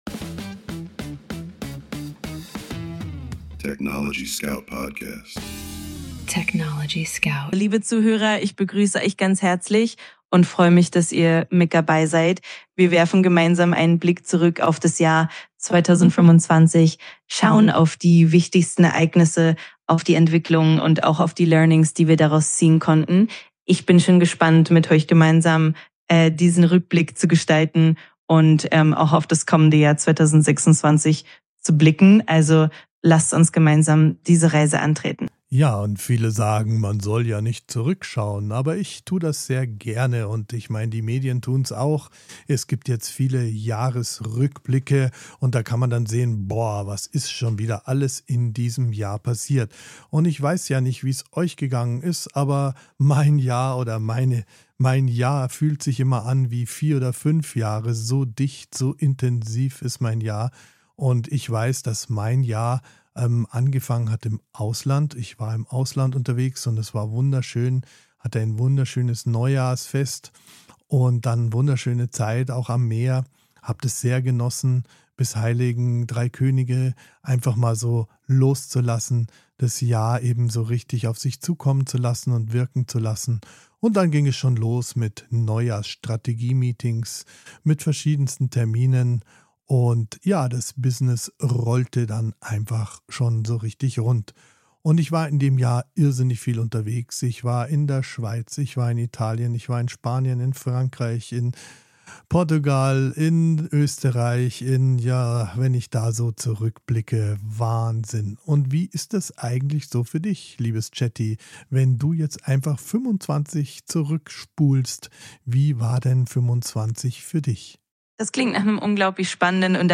Mensch und KI sprechen miteinander – nicht gegeneinander.